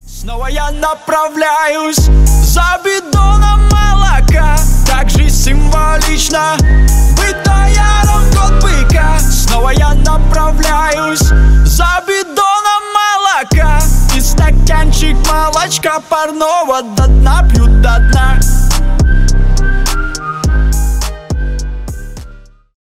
• Качество: 320 kbps, Stereo
Рэп и Хип Хоп
весёлые
пародия